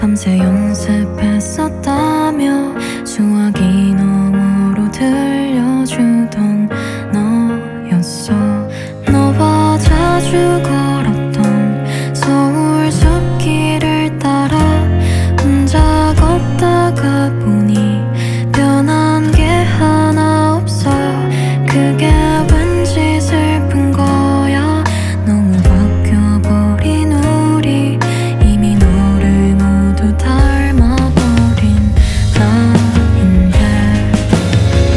Жанр: Поп / K-pop / Альтернатива